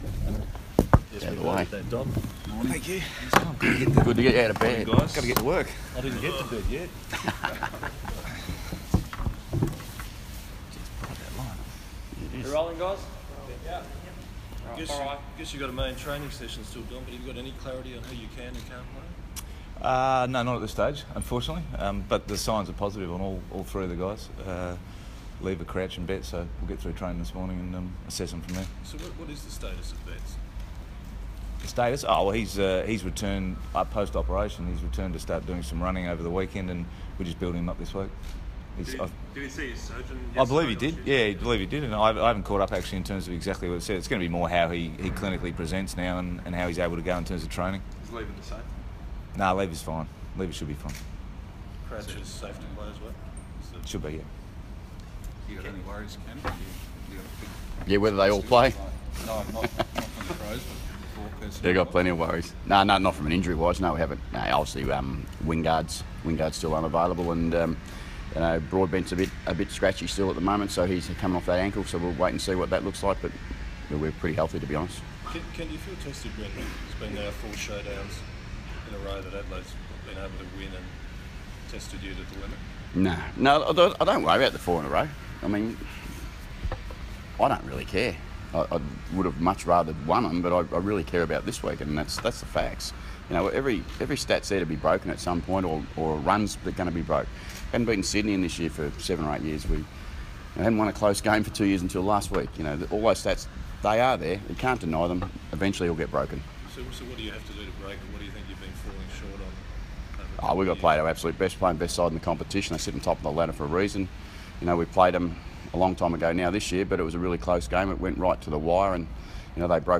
Showdown Coaches' Press Conference - Thursday, 3 August, 2017
Ken Hinkley and Don Pyke talk with media ahead of Sunday's Showdown 43.